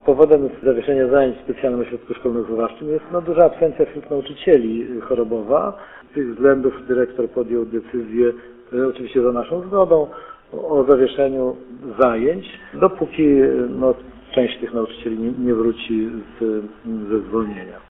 Mówi Radiu 5 starosta ełcki Marek Chojnowski.